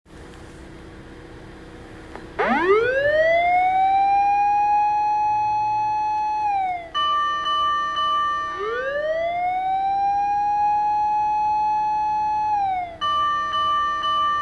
火災出動時
「ウ～・カン、カン、カン」
サイレン音（火災） （mp3） (音声ファイル: 230.6KB)
消防自動車（救急車は除く）は、火災現場に向かう時は、「ウ～」というサイレンの音に加えて、「カン、カン、カン」という鐘の音を鳴らして走行しています。